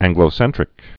(ăngglō-sĕntrĭk)